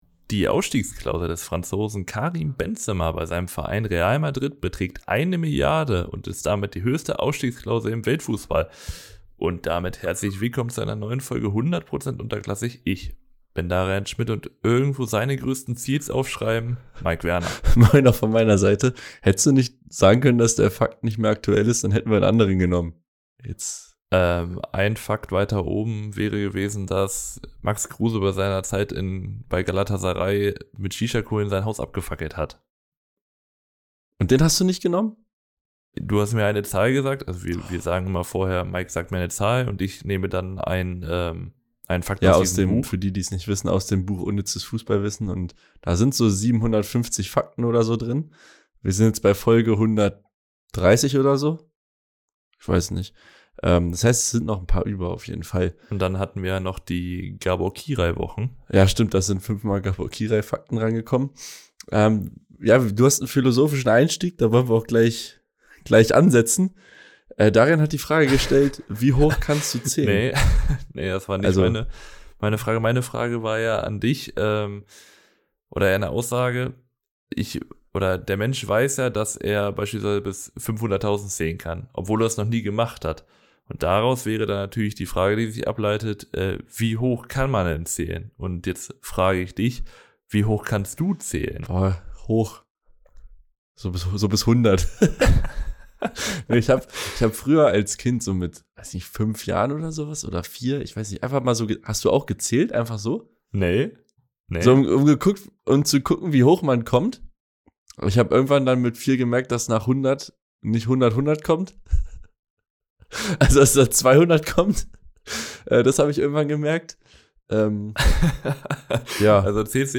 Beschreibung vor 10 Monaten Es gab ein paar technische Probleme bei der Aufnahme, daher habe ich meine Aussagen in akribischer Kleinarbeit nochmals aufgenommen, um das Audiostück beizubehalten.